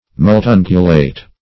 Multungulate \Mul*tun"gu*late\